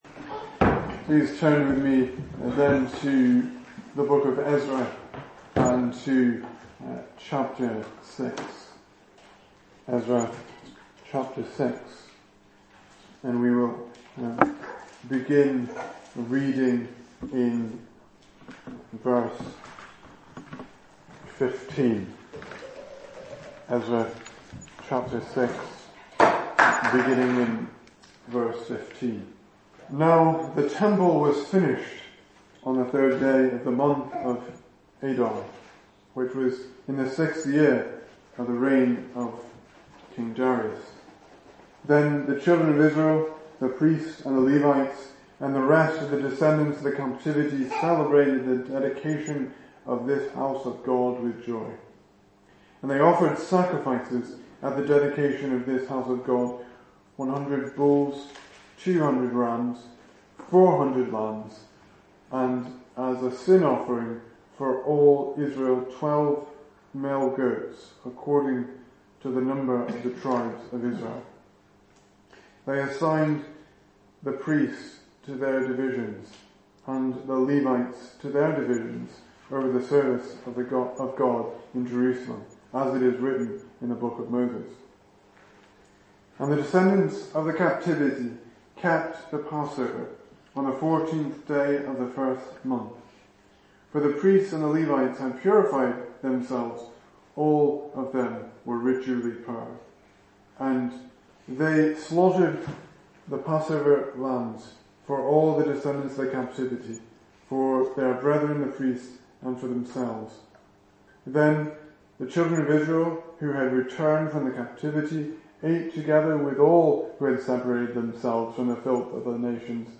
2018 Service Type: Sunday Evening Speaker